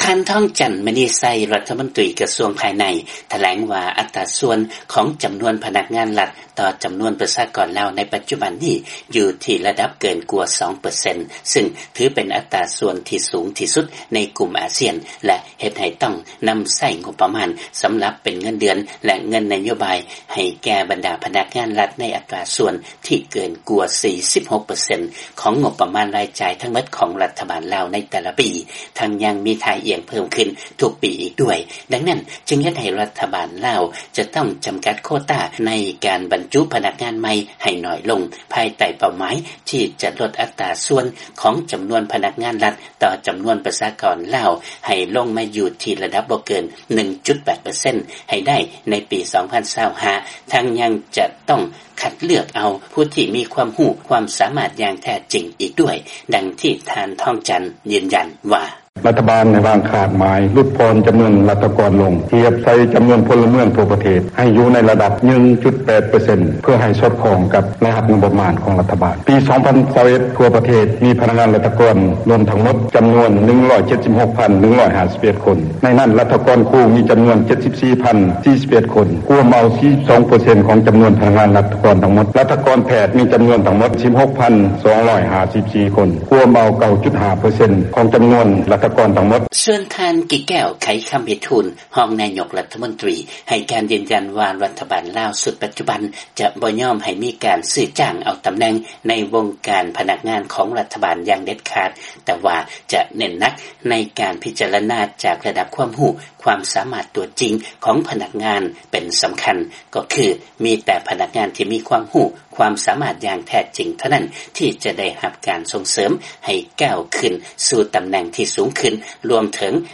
ມີລາຍງານເລື້ອງນີ້ຈາກບາງກອກ.